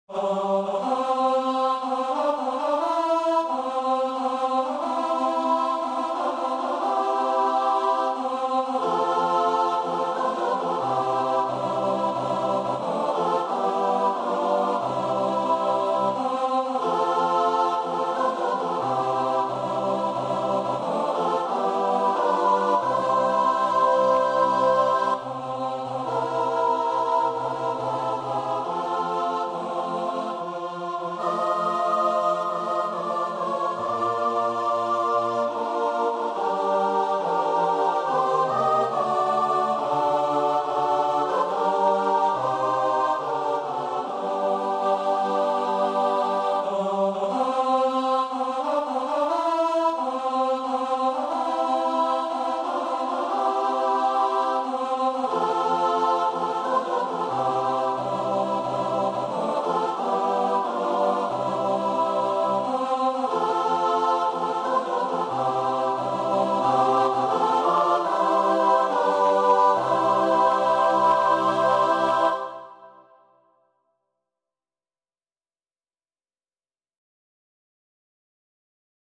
SATB (4 voix mixtes) ; Partition complète.
Pièce chorale.
Tonalité : do majeur